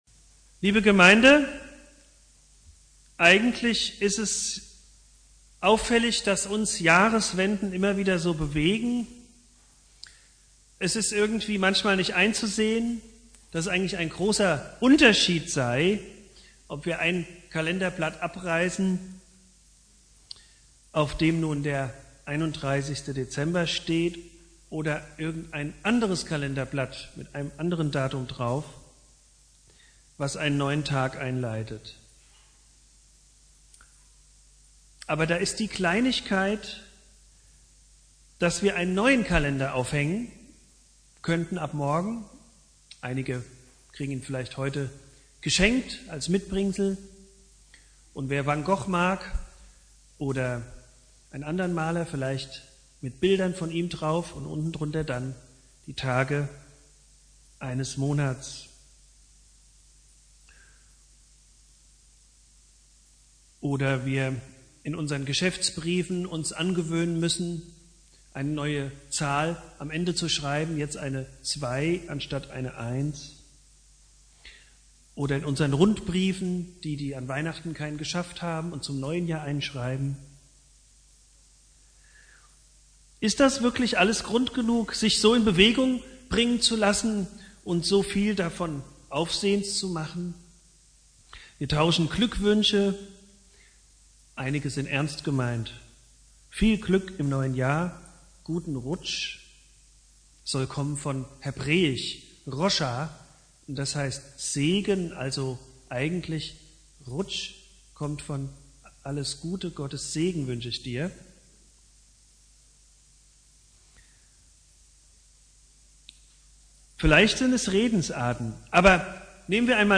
Predigt
Silvester